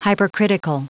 Prononciation du mot hypercritical en anglais (fichier audio)